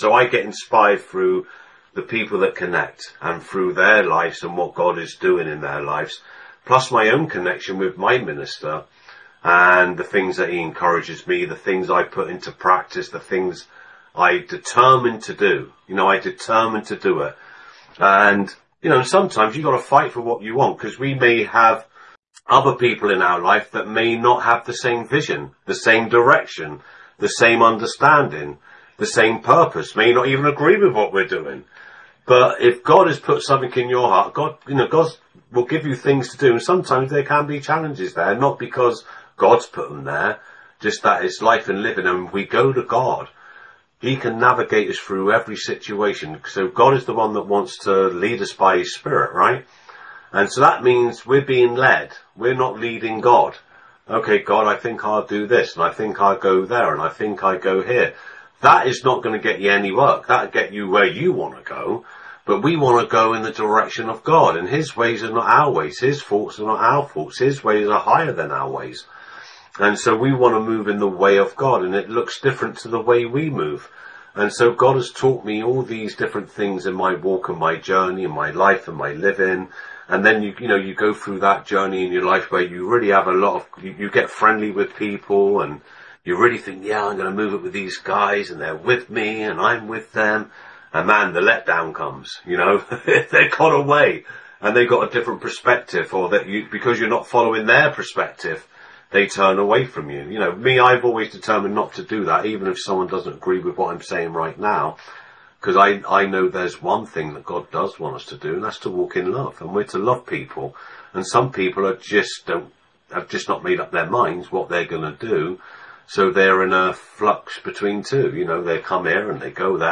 From June Monthly Leaders Meeting